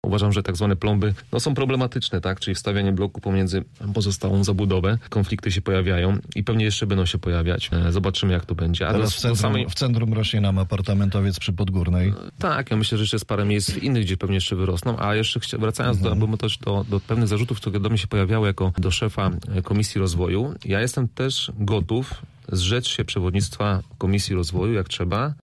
Przewodniczący klubu Zielona Razem w Rozmowie Punkt 9 mówił o koncepcji na osiedlu Leśny Dwór: